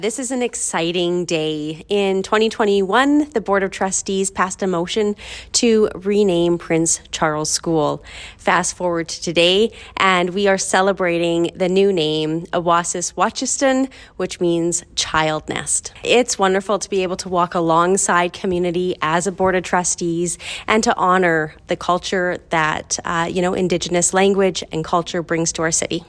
Julie Kusiek, Board Chair with Edmonton Public Schools spoke with CFWE about the name change and adds that the board feels wonderful to be able to walk alongside community in honoring the culture that Indigenous languages bring to Edmonton.